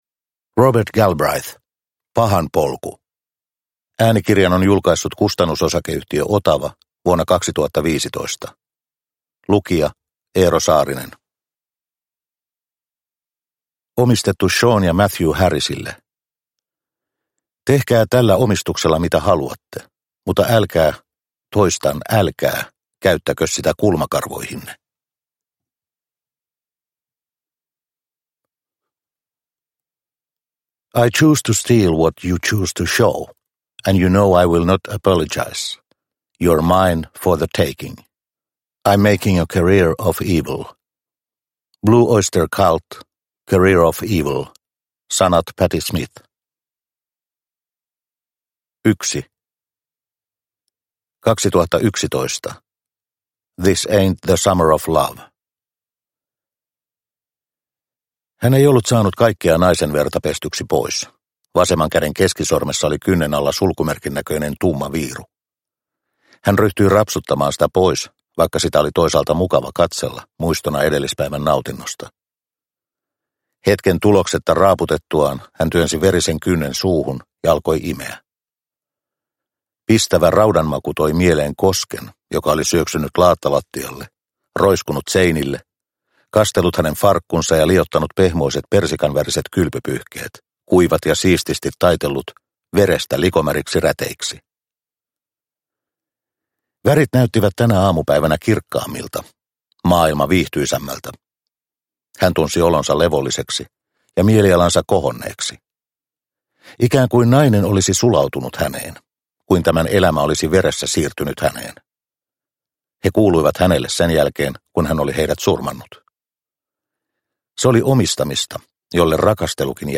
Pahan polku – Ljudbok – Laddas ner